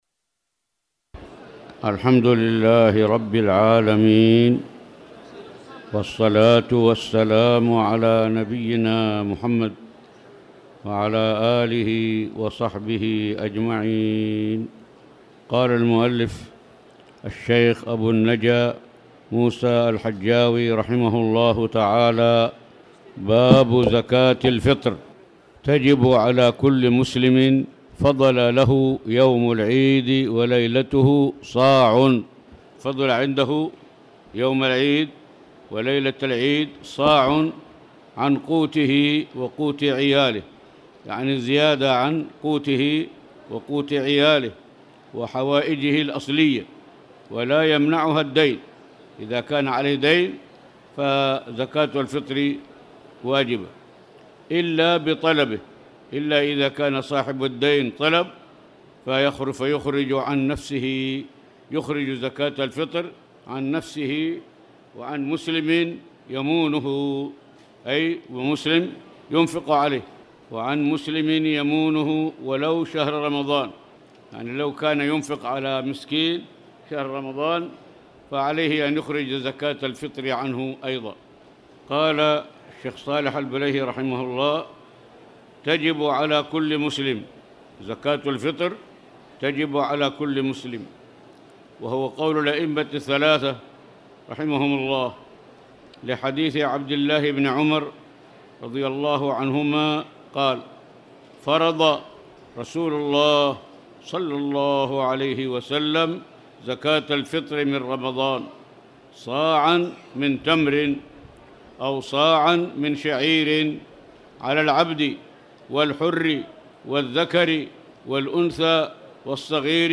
تاريخ النشر ١٨ رمضان ١٤٣٨ هـ المكان: المسجد الحرام الشيخ